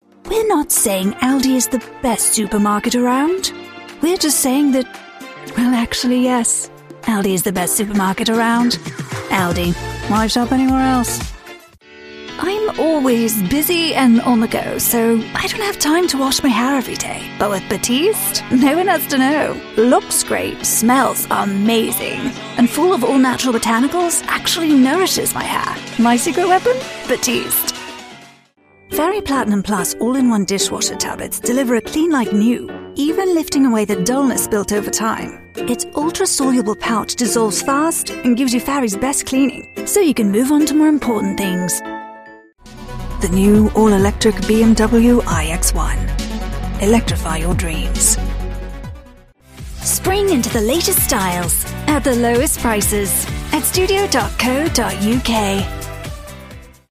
Demo
Teenager, Young Adult, Adult
Has Own Studio
british rp | natural